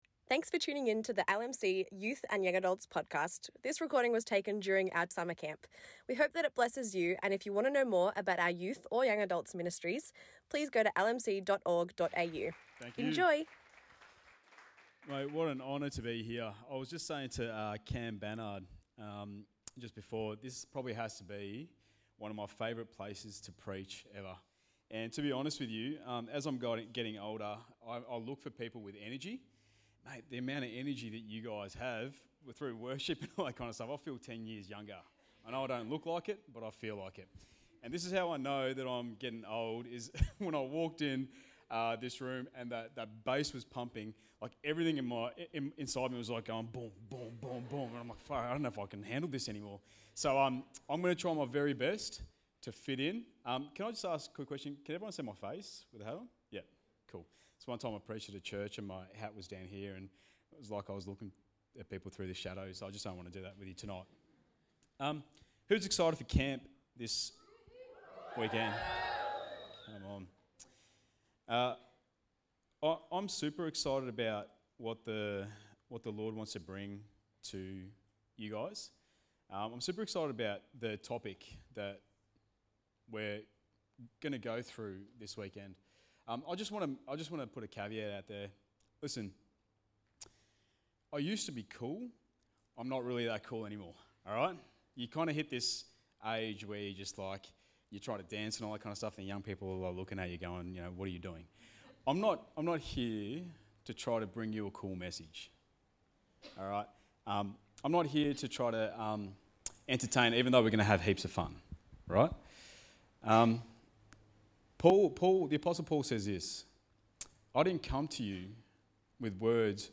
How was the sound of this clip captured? Camp 2023 - Friday Night